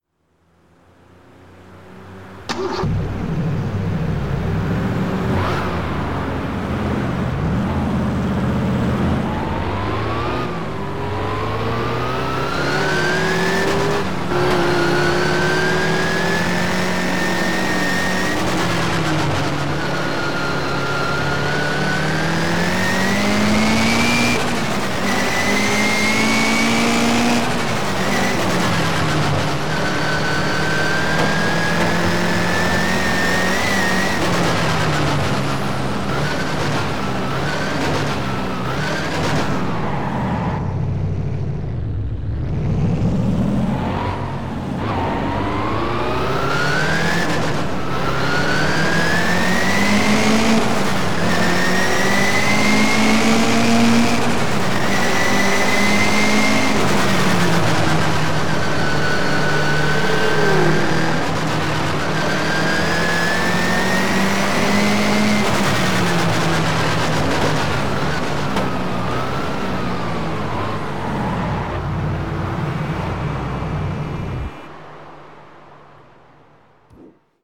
TDU 1 - Sound mods